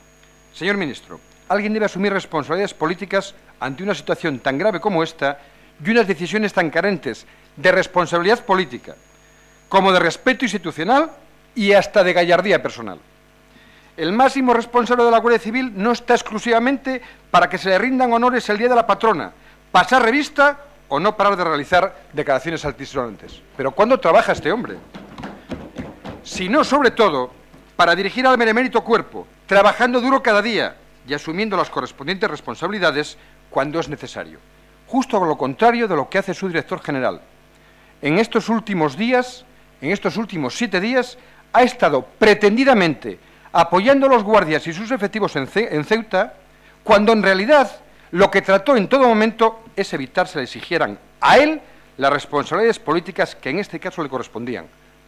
Fragmento de la intervención de Antonio Trevín en la Comisión de Interior el 13/02/2014. Pide responsabilidades políticas al Director de la Guardia Civil tras la muerte de 11 inmigrantes en Ceuta